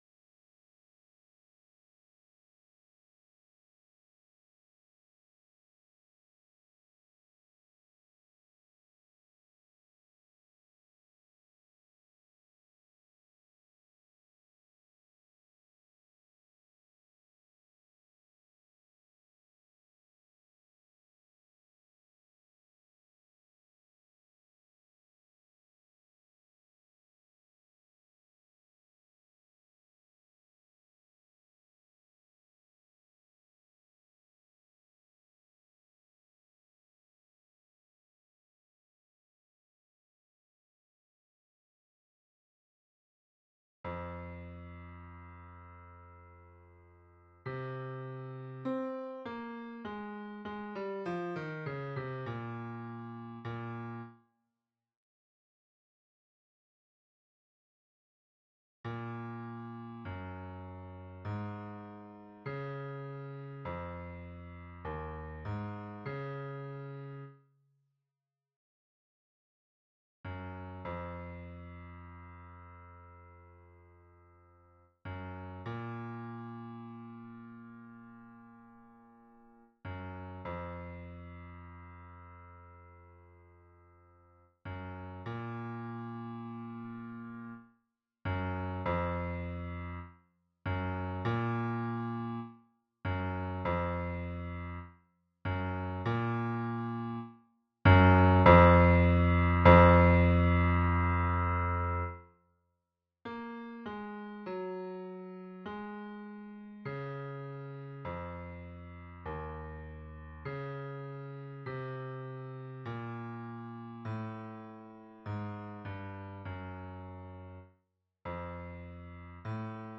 Basse (version piano)